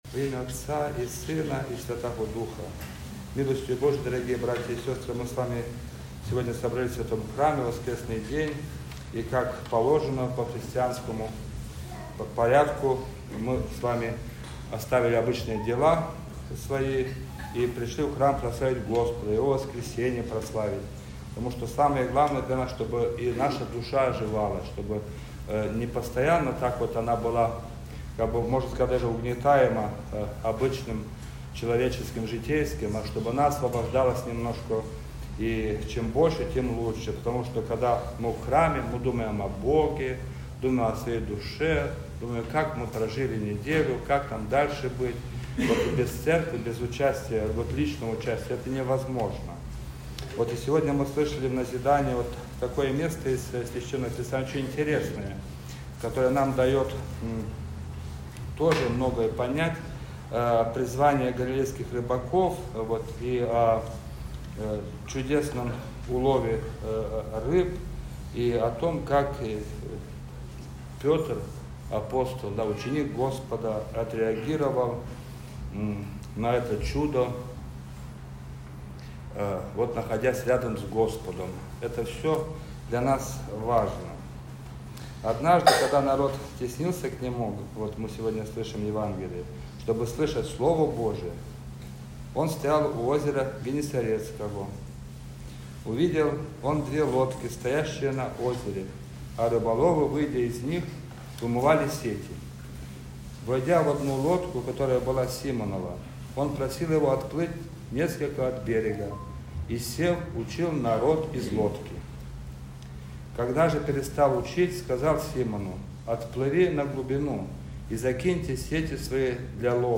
Проповедь